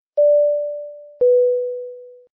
airbus-cabin-chime_25163.mp3